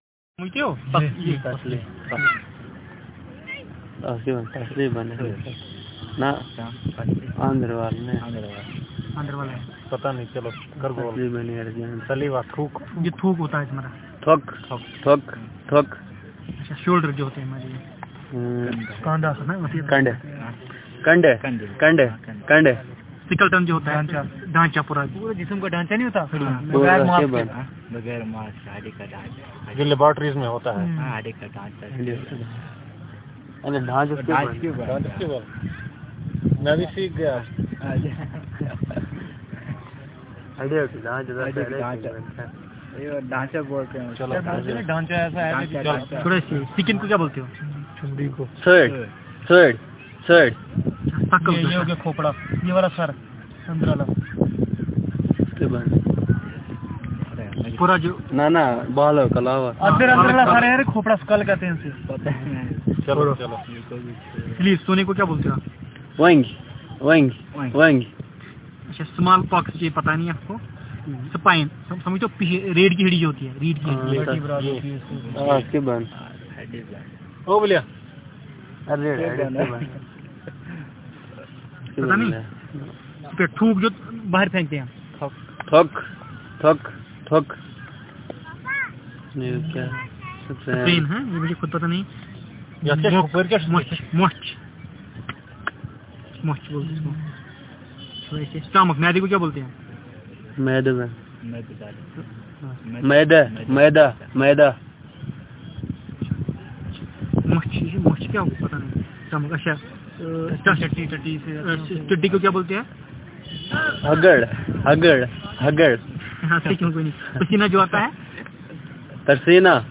Elicitation of words about body conditions, body secretions, body parts
dc.description.elicitationmethodInterview method